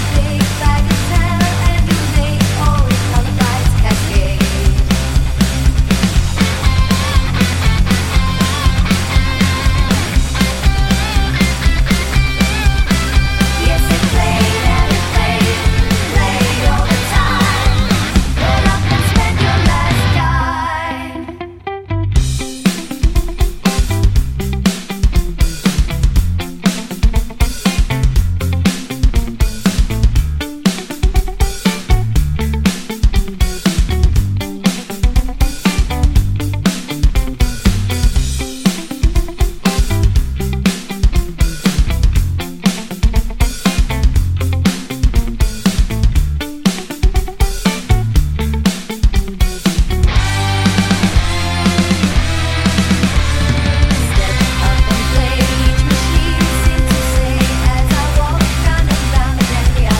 Rock Version Pop (1960s) 2:56 Buy £1.50